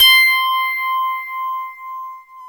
Index of /90_sSampleCDs/Club-50 - Foundations Roland/PNO_xFM Rhodes/PNO_xFM Rds C x2